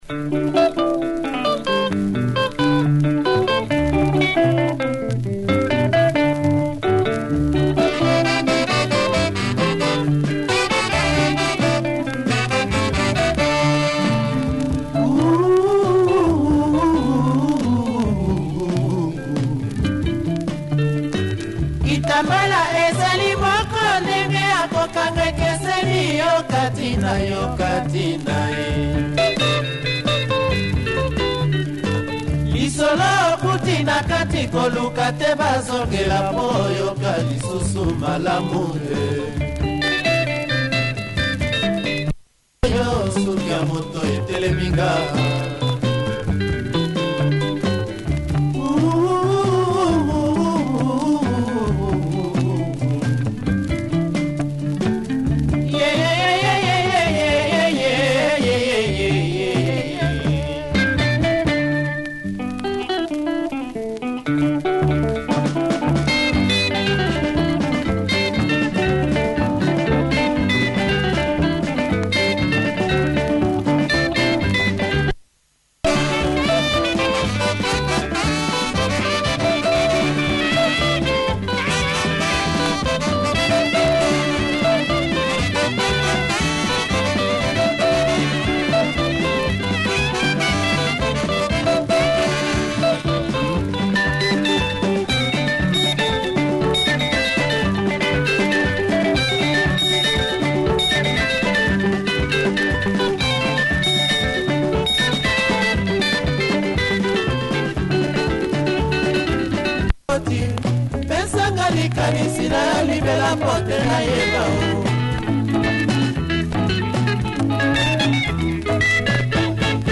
Classic Congolese Lingala